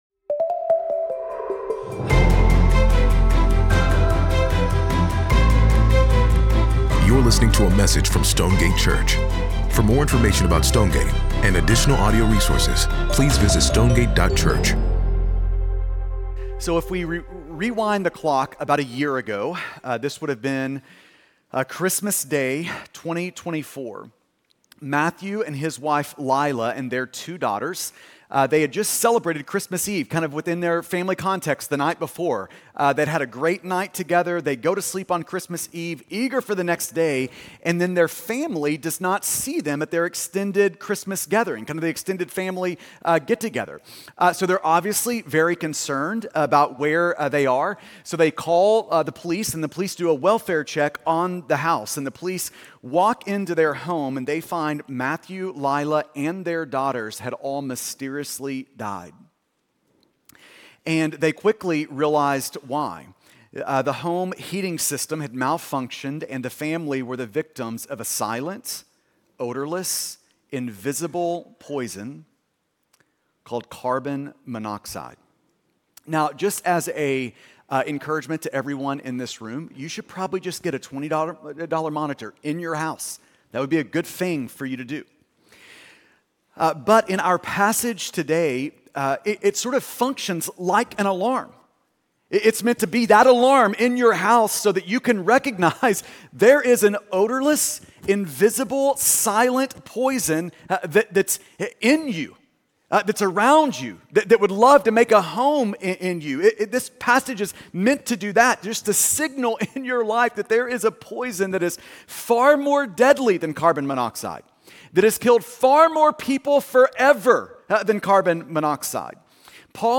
Sermon Podcast - 12.7.mp3